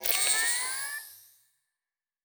pgs/Assets/Audio/Sci-Fi Sounds/Electric/Device 6 Start.wav at 7452e70b8c5ad2f7daae623e1a952eb18c9caab4